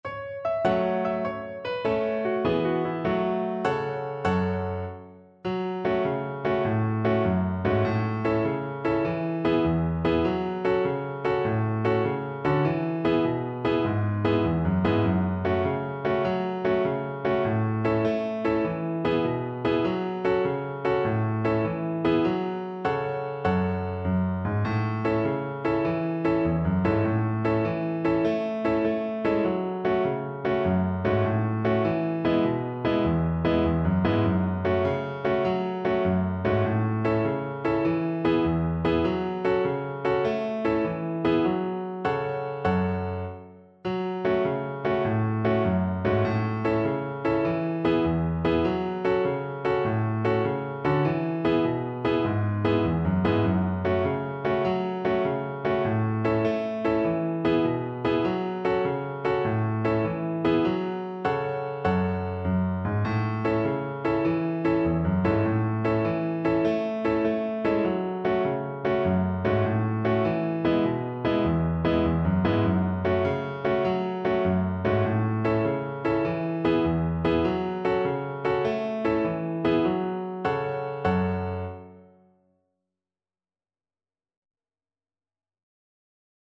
Traditional Trad. Hot Ashphalt Violin version
Violin
F# minor (Sounding Pitch) (View more F# minor Music for Violin )
Allegro .=c.100 (View more music marked Allegro)
6/8 (View more 6/8 Music)
Traditional (View more Traditional Violin Music)